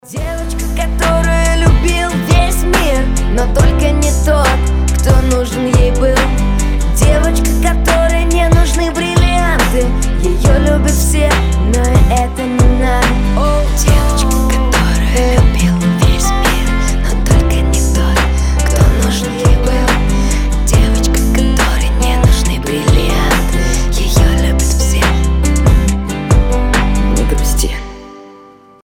• Качество: 320, Stereo
гитара
мужской голос